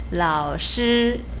lao3-shi1 = litt. "old master", a common word for teacher.